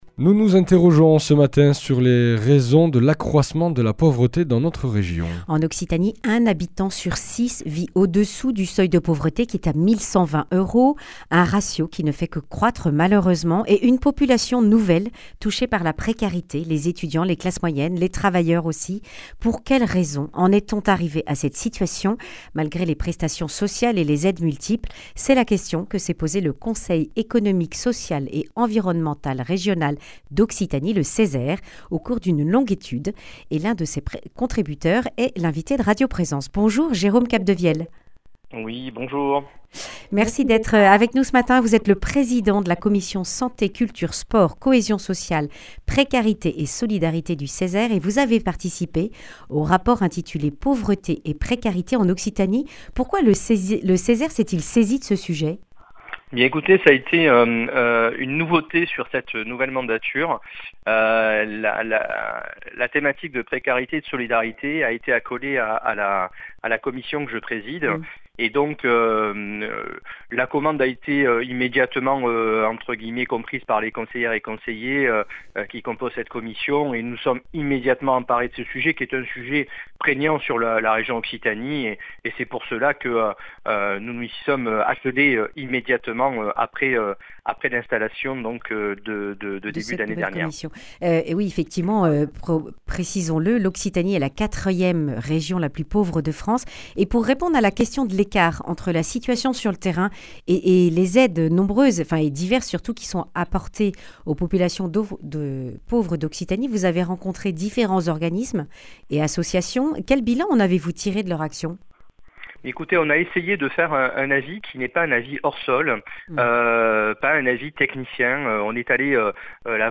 Accueil \ Emissions \ Information \ Régionale \ Le grand entretien \ Comment lutter contre la pauvreté et la précarité en Occitanie ?